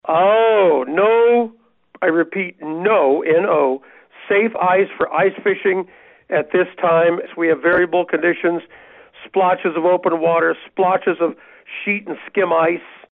Weekly Conversation